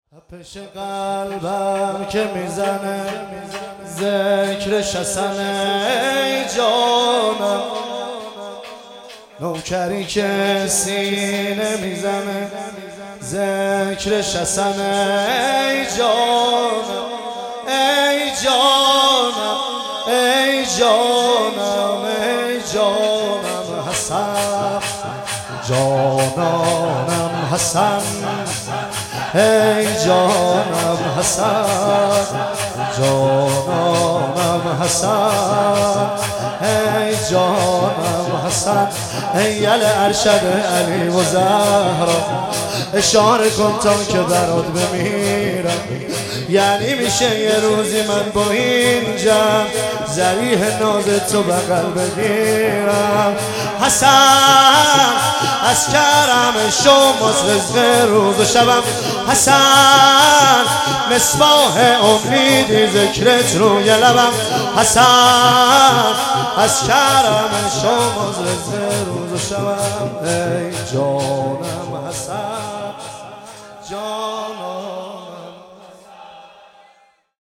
مراسم شب ۲ صفر ۱۳۹۷
دانلود   شور